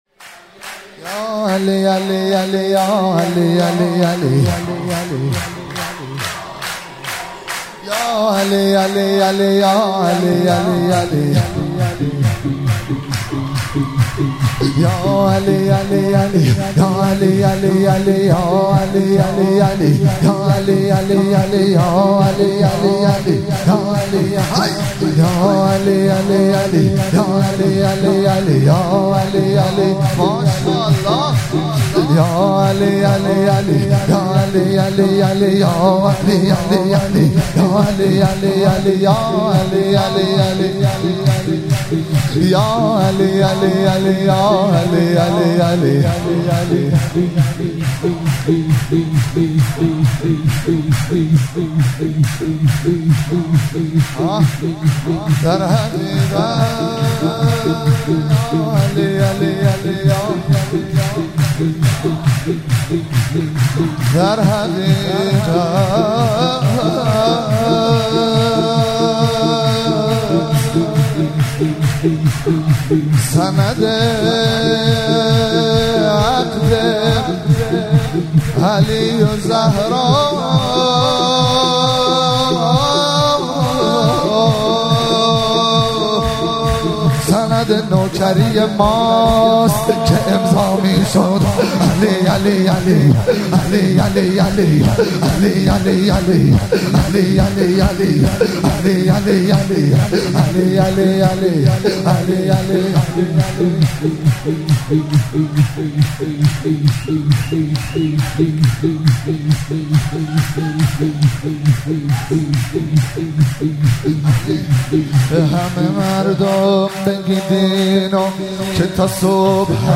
مراسم جشن سالگرد ازدواج حضرت امیرالمومنین علی علیه السلام و حضرت فاطمه زهرا سلام الله علیها- خرداد 1402
شور- به همه مردم بگید اینو که تا صبح همین بساطه